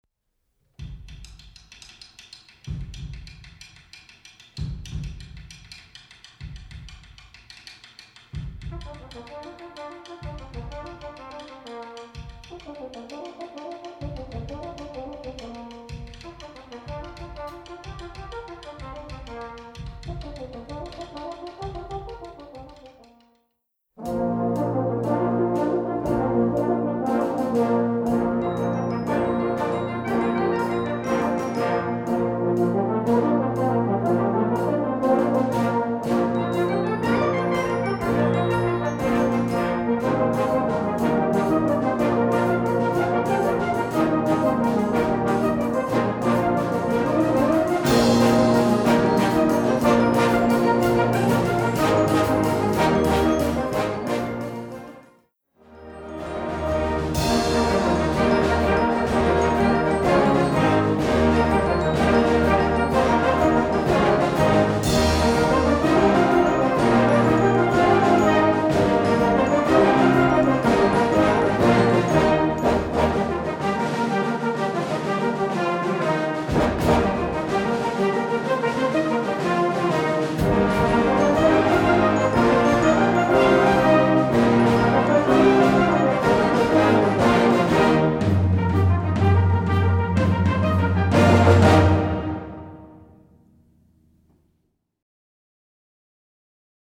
Gattung: Konzertwerk
3:00 Minuten Besetzung: Blasorchester PDF